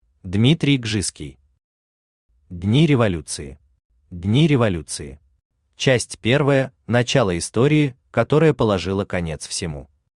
Aудиокнига Дни революции Автор Дмитрий Кжиский Читает аудиокнигу Авточтец ЛитРес.